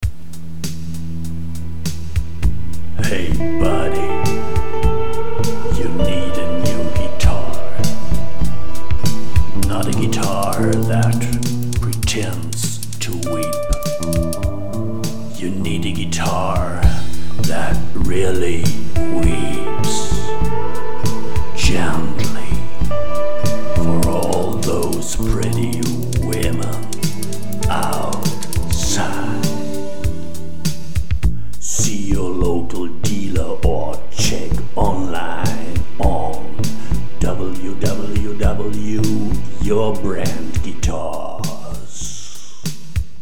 Radio and Internet Spot Samples
Guitar Brand sample...